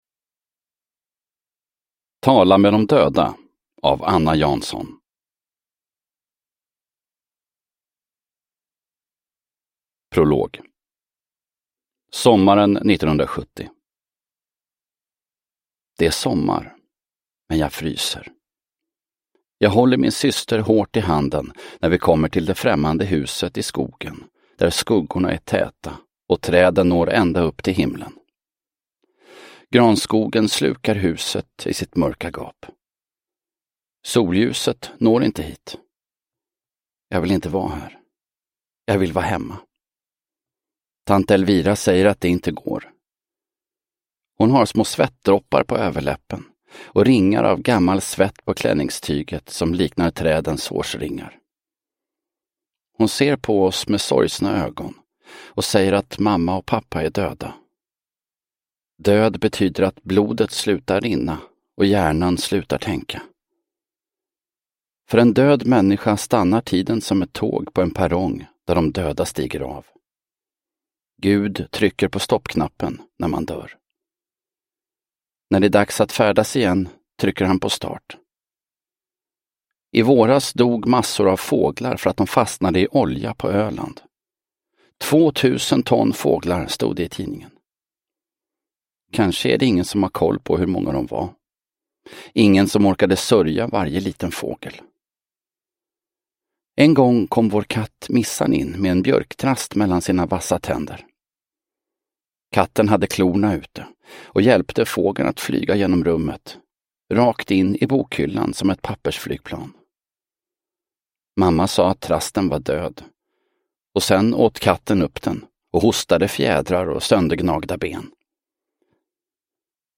Tala med de döda – Ljudbok – Laddas ner